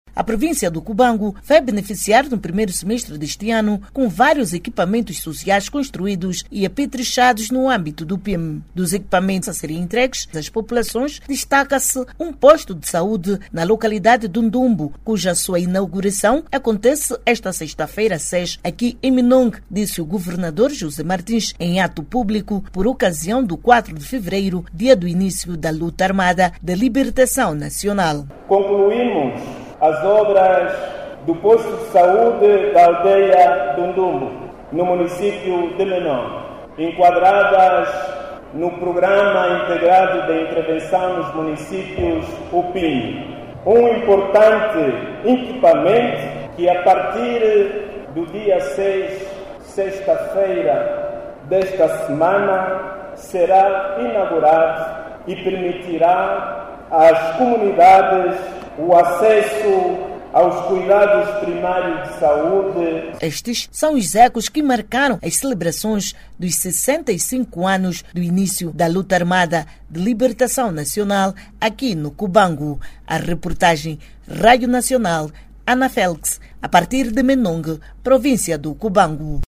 A província do Cubango, vai, nos próximos dias, ganhar novos equipamentos sociais no âmbito do PIIM. Já amanhã, sexta-feira, 06, será inaugurado um posto de saúde na localidade do Ndumbo. Ouça o desenvolvimento desta matéria na voz do jornalista